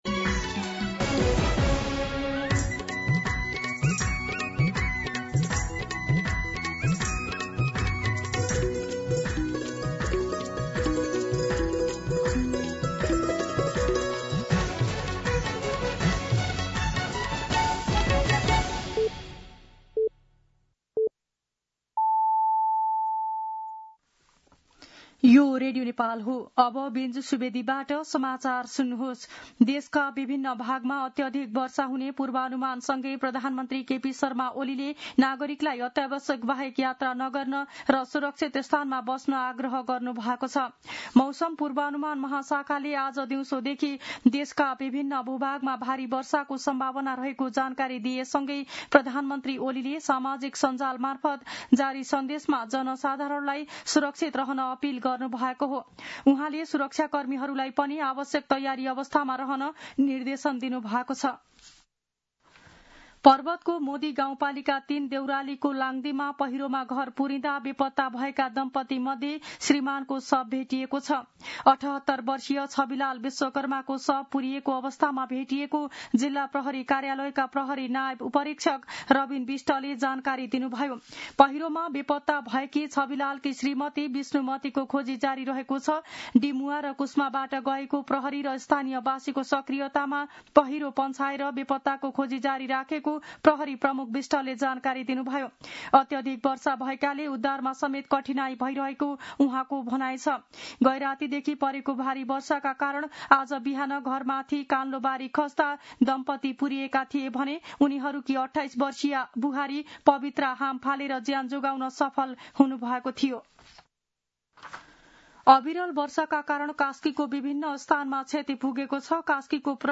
दिउँसो १ बजेको नेपाली समाचार : ४ साउन , २०८२
1pm-News-04.mp3